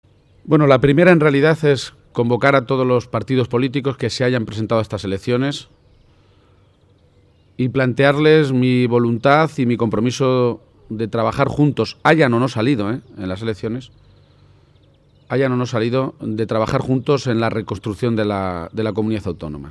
Audio Page-presentación programa electoral 3